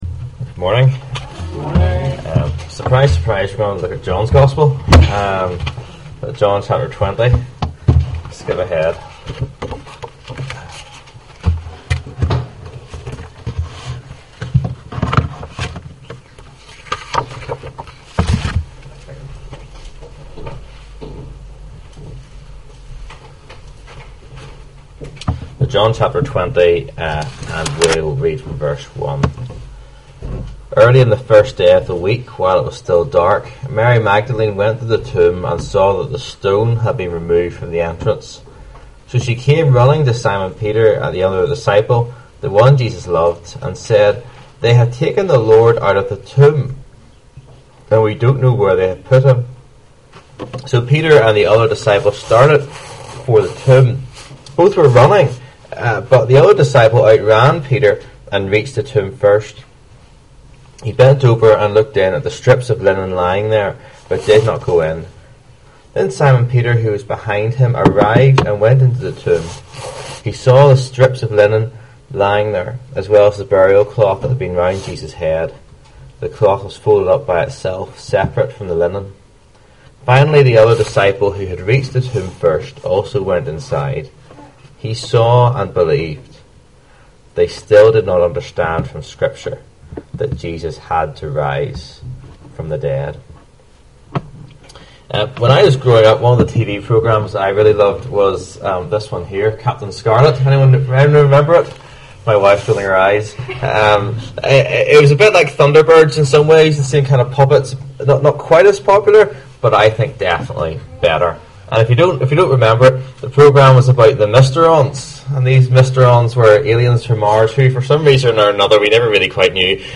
Service Type: 11am